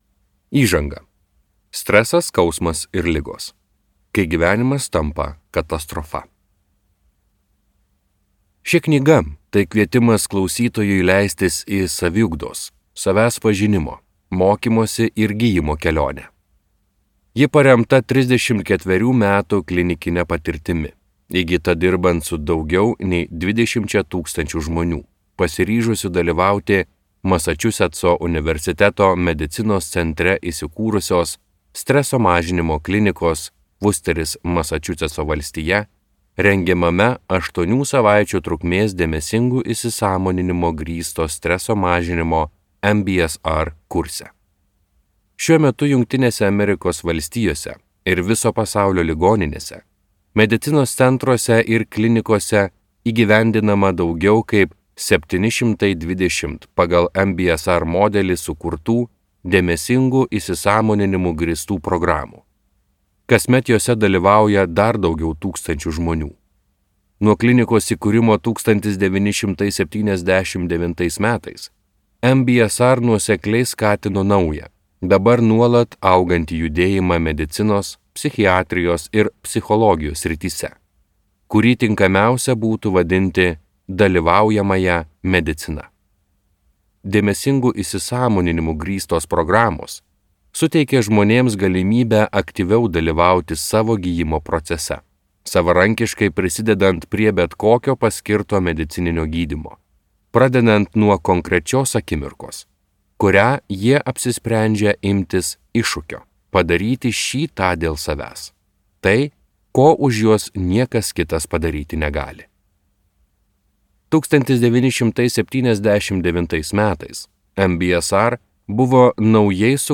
Skaityti ištrauką play 00:00 Share on Facebook Share on Twitter Share on Pinterest Audio Kūno ir proto išmintis.